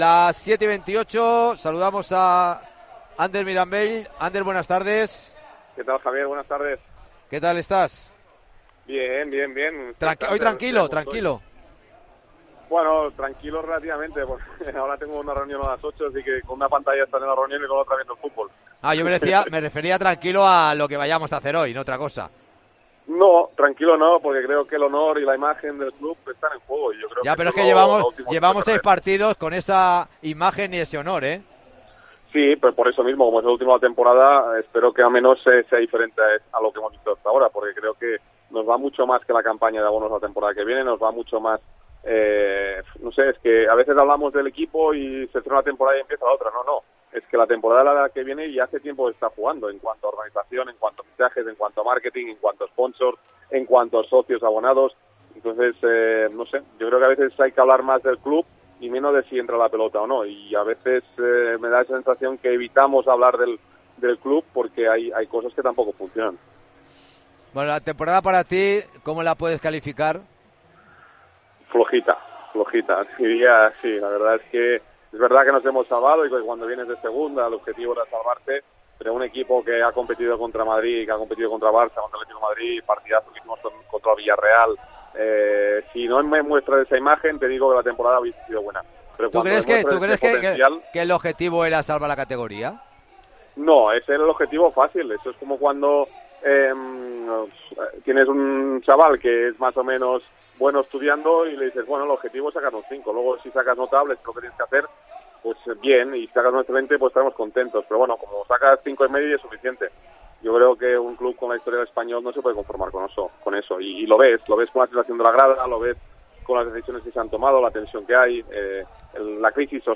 Entrevista Ander Mirambell